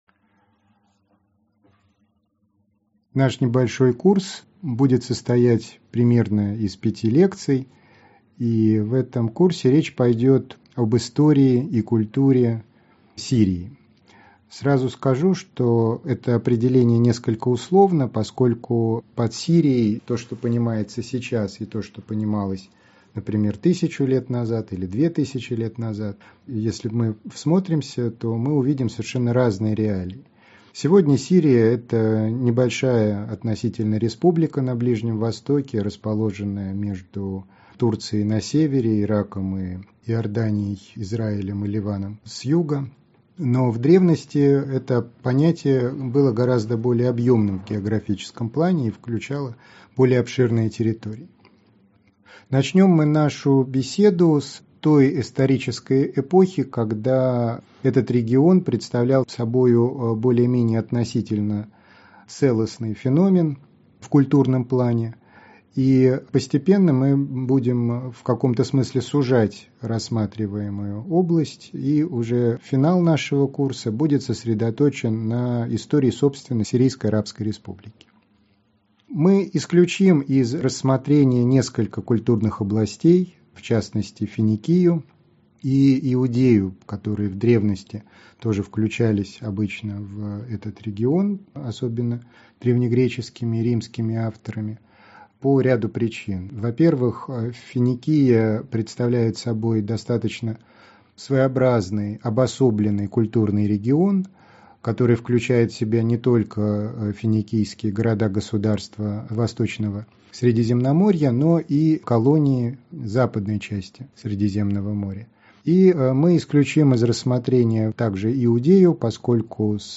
Аудиокнига Великая предыстория | Библиотека аудиокниг